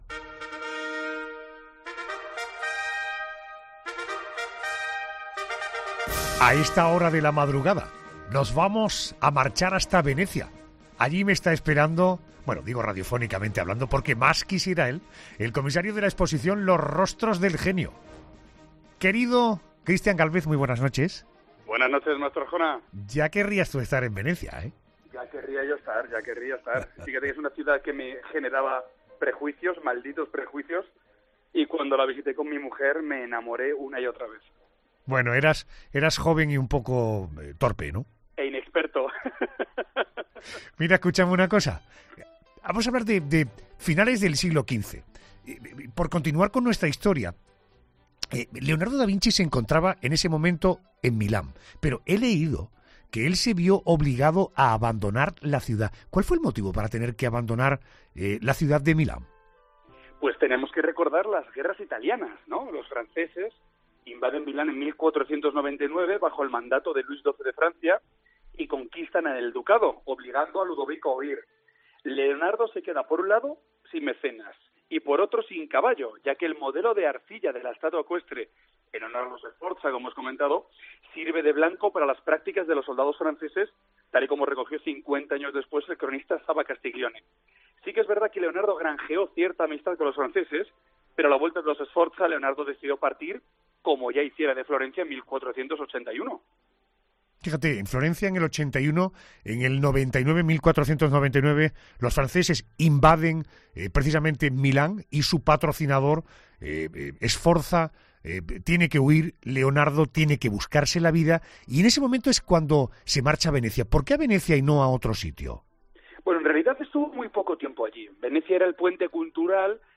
Lo ha contado en La Noche de COPE el comisario de la exposición “Los rostros del genio”, Christian Gálvez en una nueva entrega de la vida de este genio.